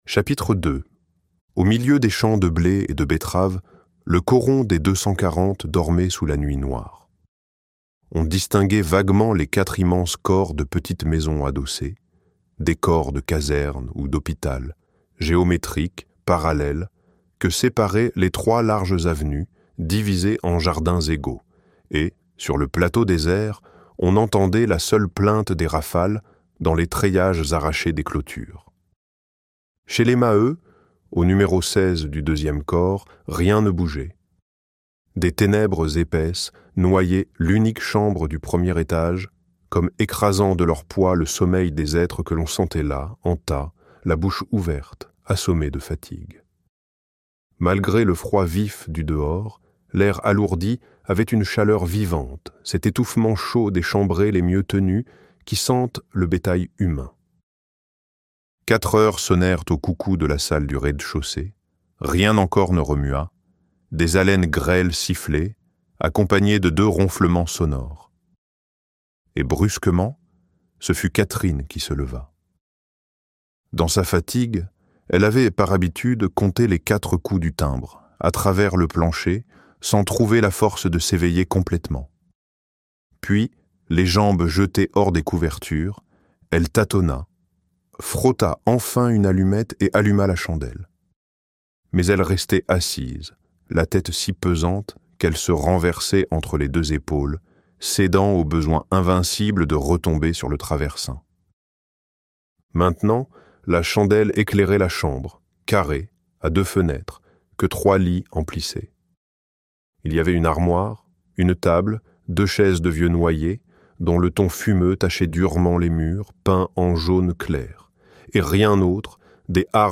Germinal - Livre Audio